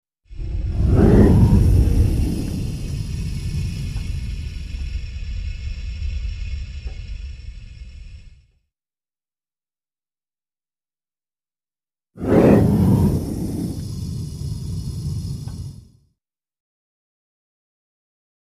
Furnace | Sneak On The Lot
Gas Furnace Ignition, Close Up To Igniter